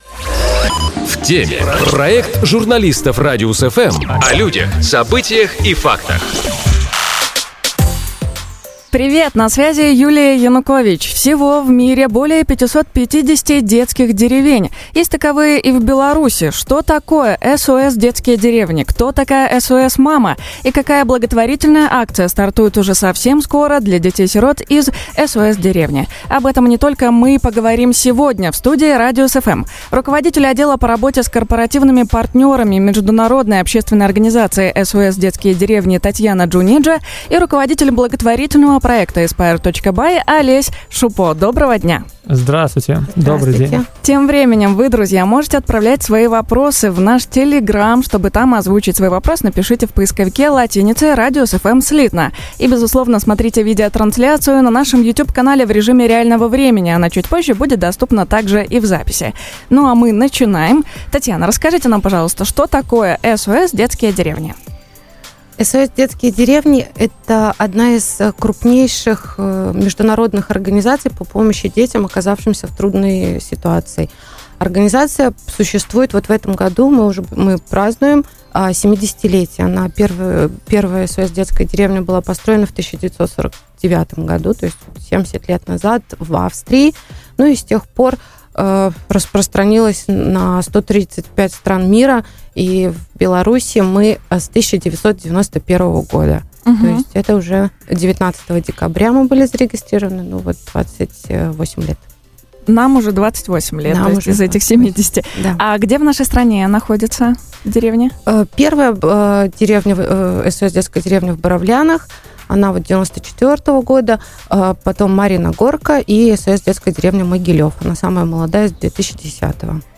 В студии «Радиус FМ»